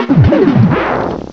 cry_not_darmanitan.aif